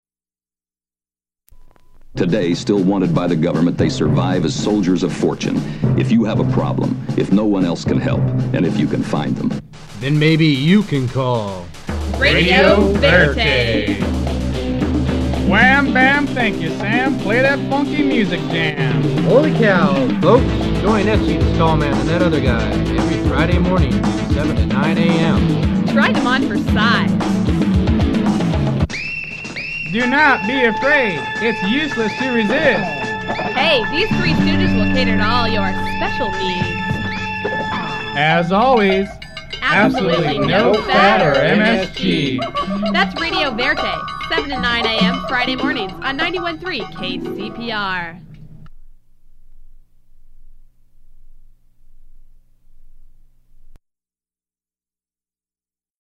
Radio Verite Friday Mornings [advertisement]
Form of original Audiocassette